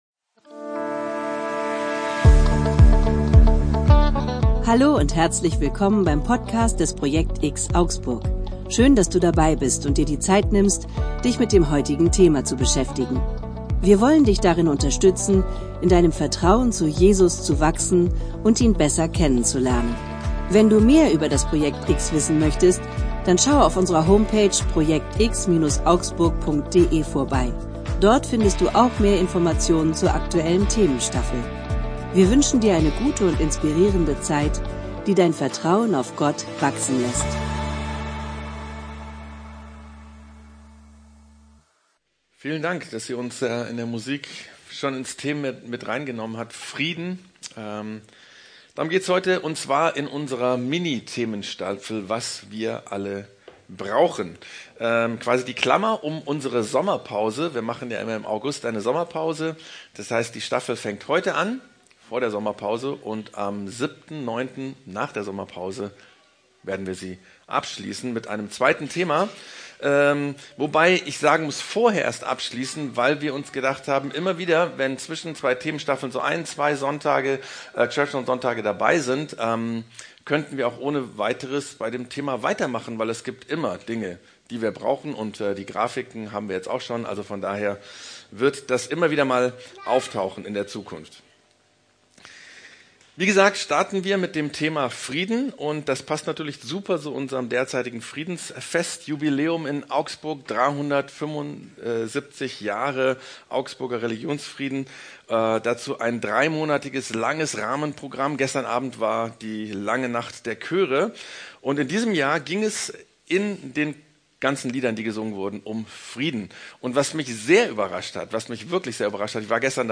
Predigten im Rahmen der church zone vom projekt_X in Augsburg